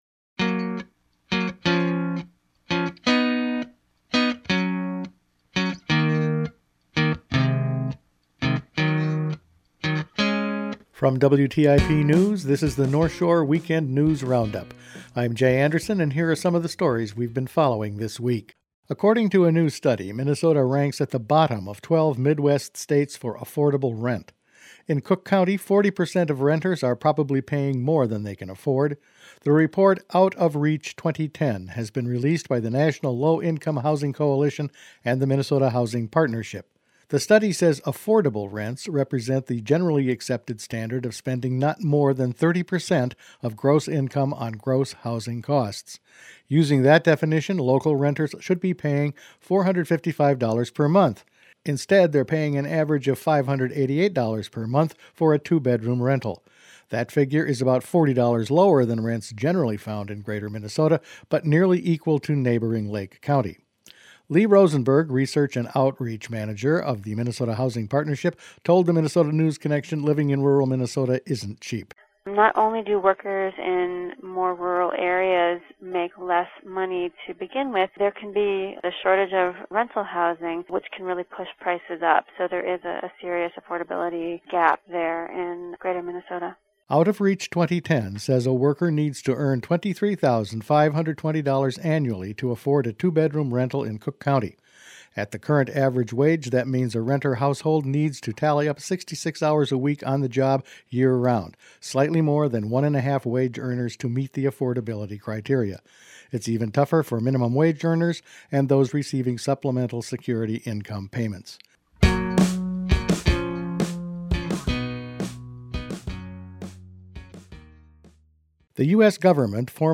Each weekend WTIP news produces a round up of the news stories they’ve been following this week. Delisted wolves, high rents, new lodging info and a report on last week’s emergency drill in the harbor were all in this week’s news.